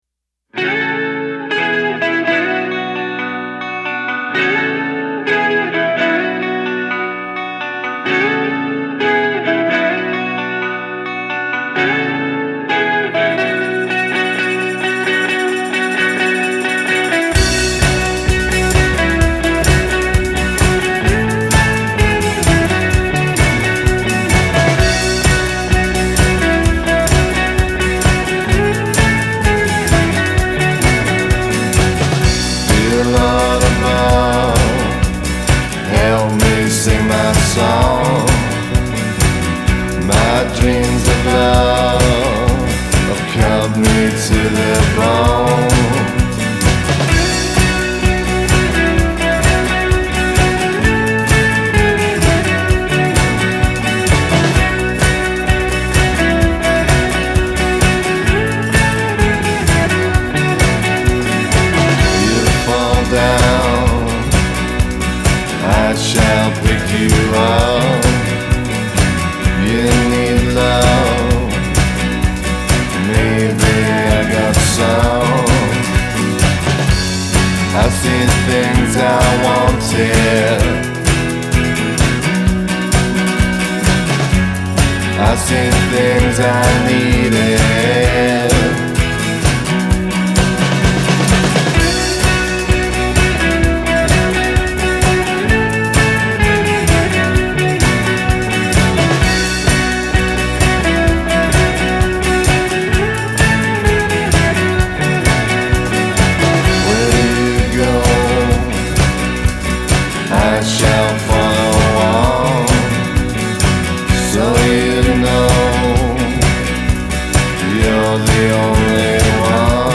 Genre: Indie/Psychedelic-Rock